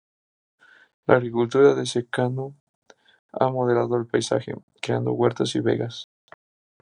/modeˈlado/